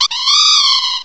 Add all new cries
cry_not_swanna.aif